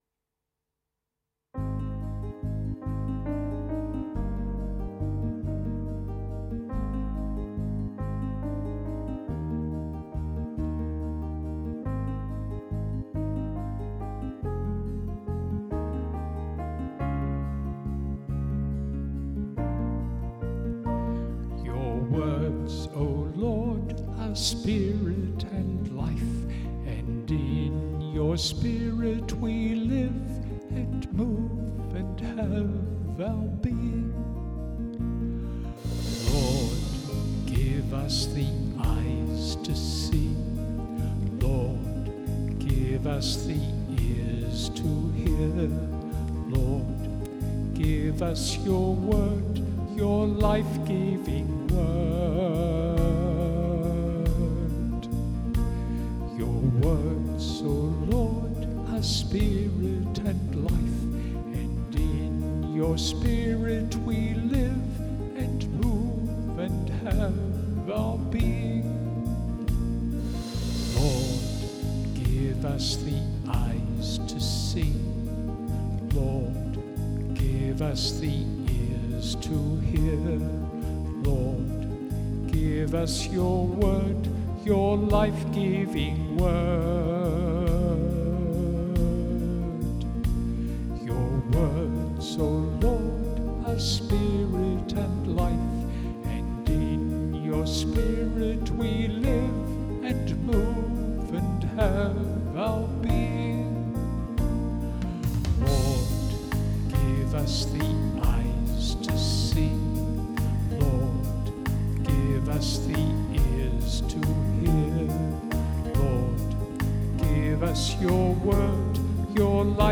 sung response for Cantor and Assembly